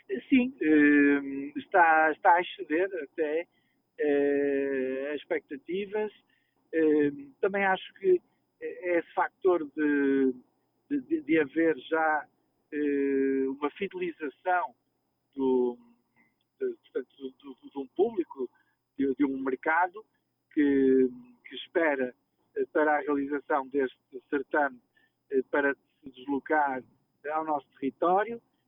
Segundo o presidente, o certame está a atrair cada vez mais público: